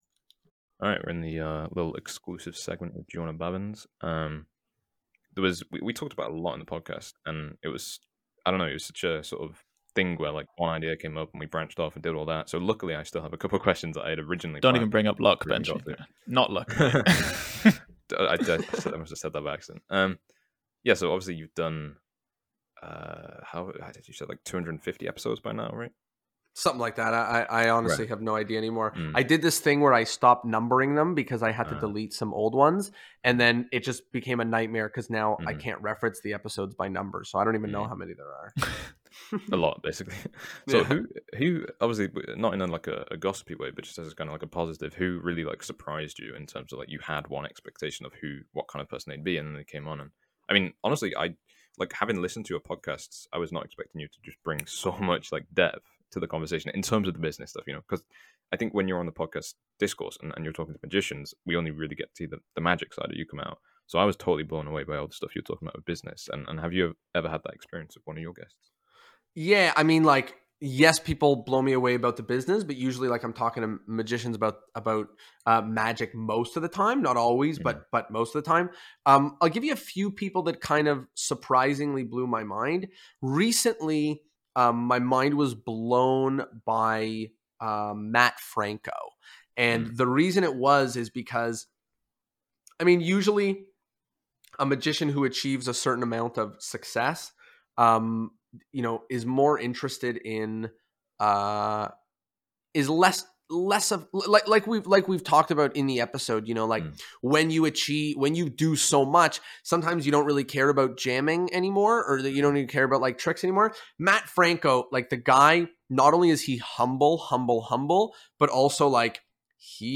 This entire conversation was recorded as a ‘bonus’ segment after the main interview,  which you can listen to here: